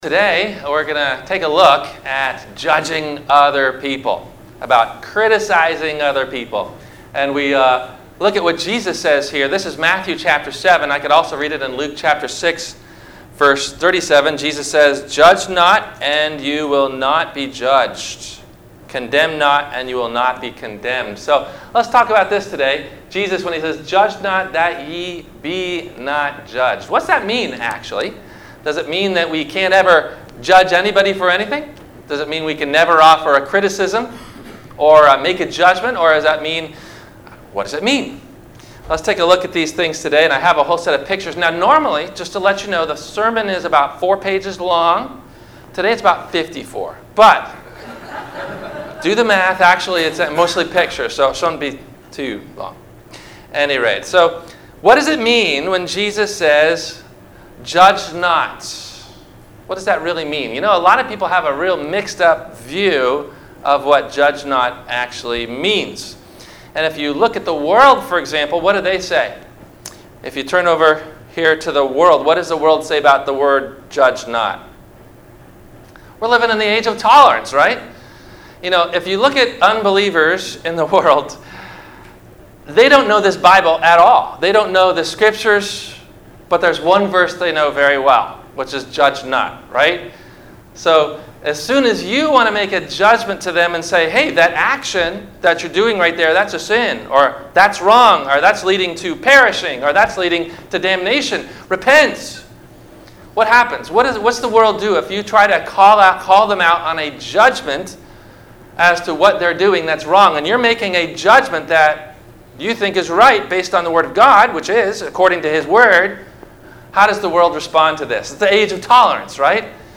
Have a Comment or Question about the Sermon?
No Questions asked before the Sermon message: